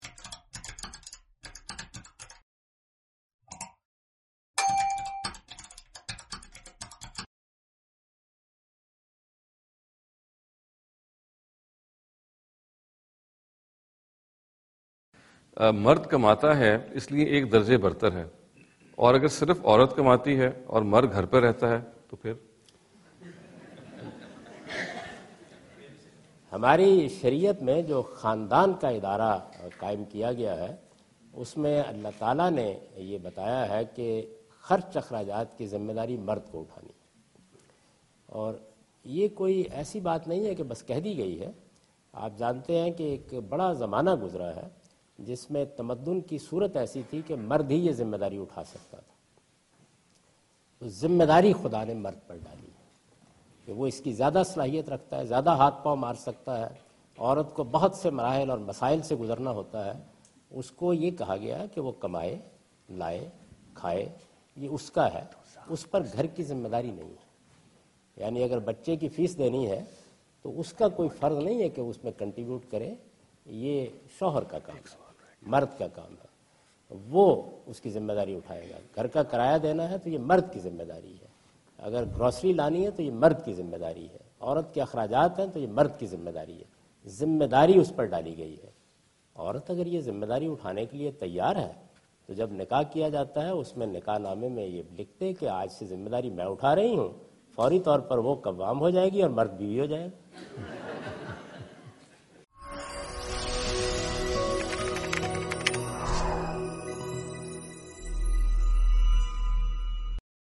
Javed Ahmad Ghamidi answer the question about "Who is Responsible for Financial Matters of a Family?" during his visit to Georgetown (Washington, D.C. USA) May 2015.
جاوید احمد غامدی اپنے دورہ امریکہ کے دوران جارج ٹاون میں "خاندان کے معاشی معاملات کا ذمہ دار کون ہے؟" سے متعلق ایک سوال کا جواب دے رہے ہیں۔